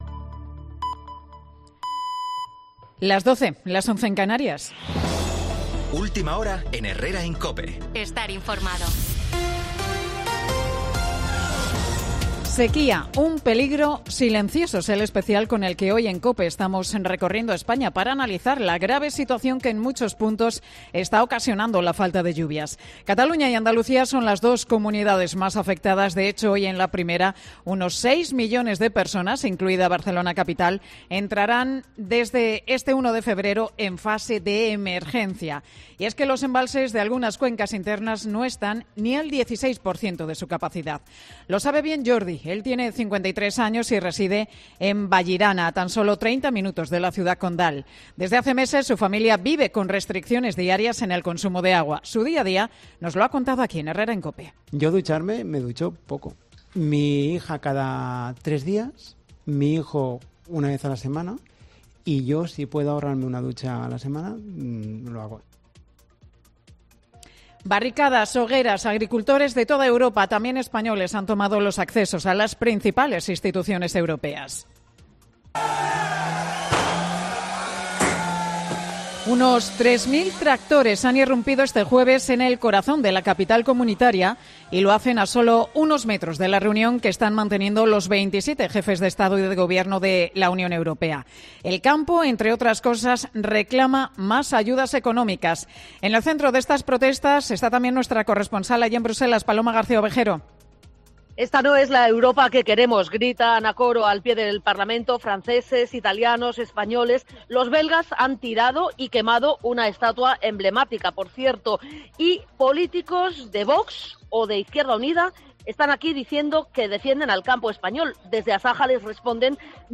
Boletín de Noticias de COPE del 1 de febrero del 2024 a las 12 horas